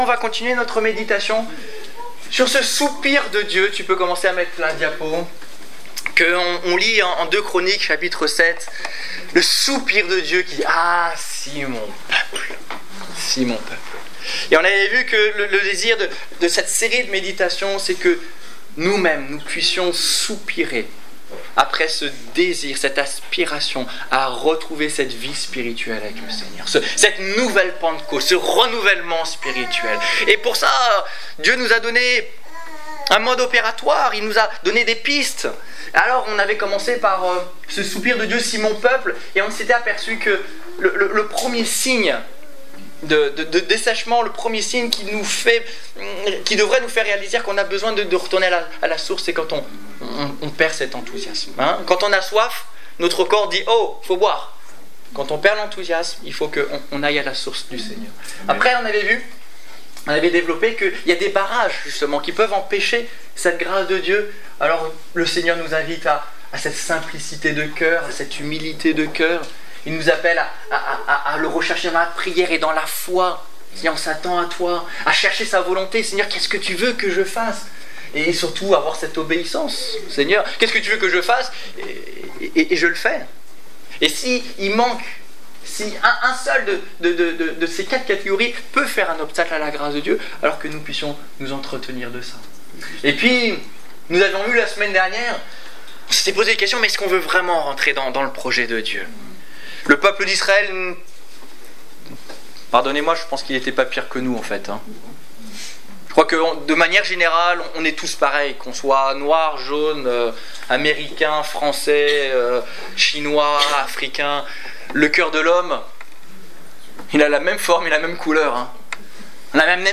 Culte du 23 novembre 2014 Ecoutez l'enregistrement de ce message à l'aide du lecteur